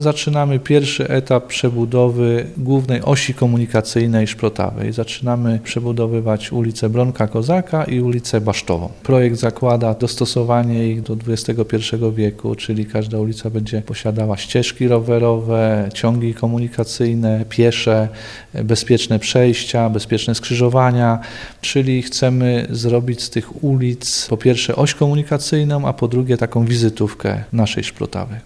– Rozpoczynamy od przebudowy ulicy Akacjowej w Wiechlicach, która prowadzi do strefy przemysłowej i modernizacji ulic Bronka Kozaka i Basztowej w Szprotawie. W centrum miasta powstanie reprezentacyjna arteria – powiedział burmistrz Mirosław Gąsik: